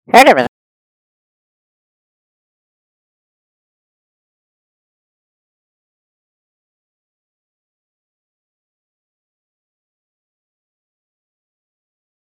altefrauspeak.mp3